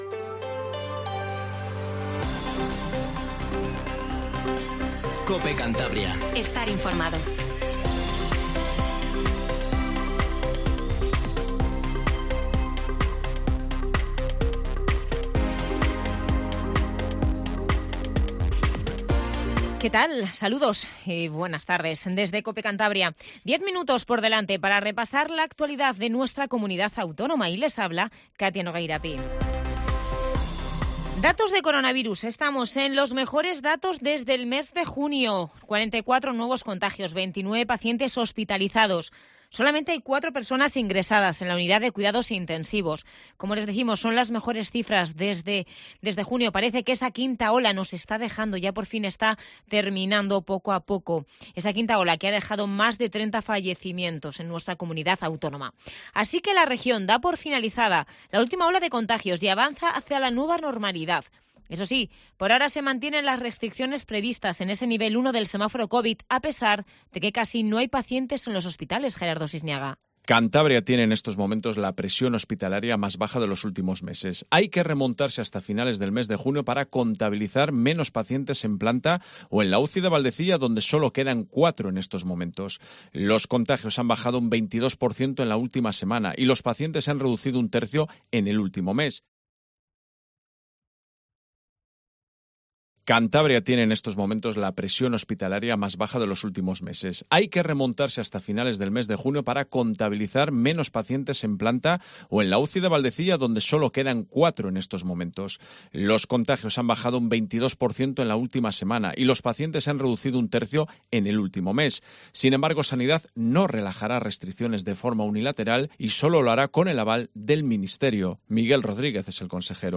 Informativo Tarde COPE CANTABRIA